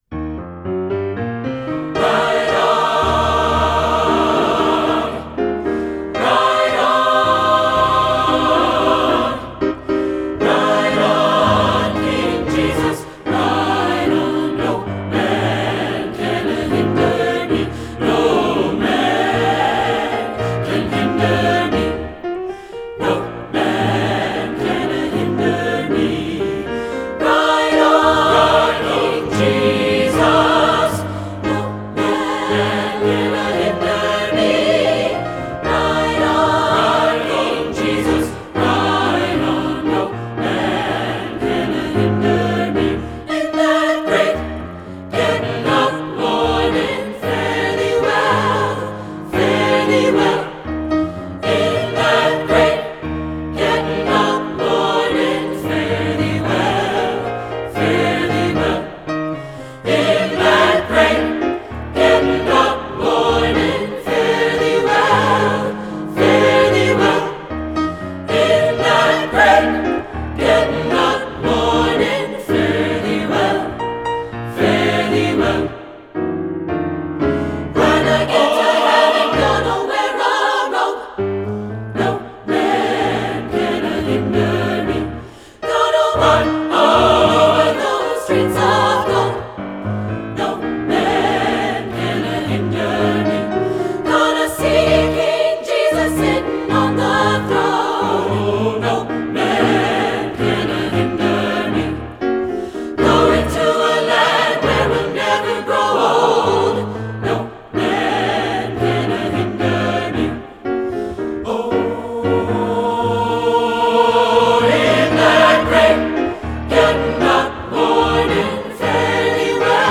Voicing: SSATB and Piano